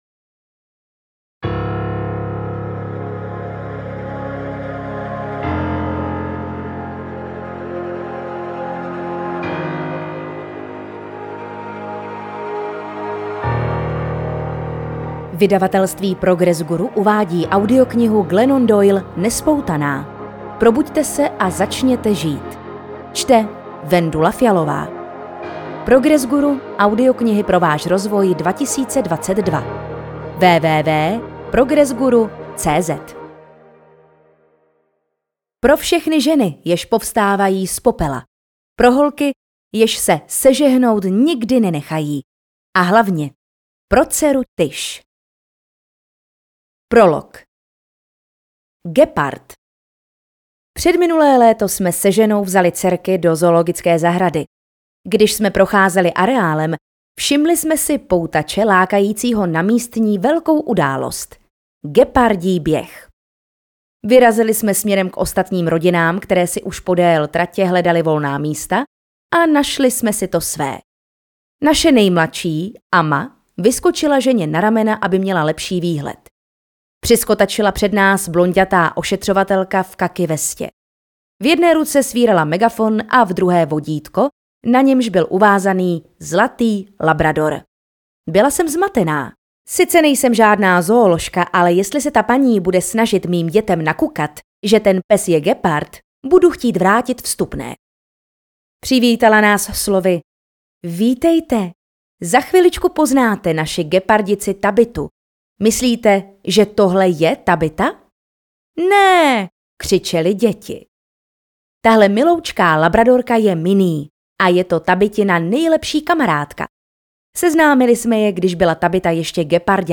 Audiokniha Nespoutaná - Glennon Doyle | ProgresGuru
audiokniha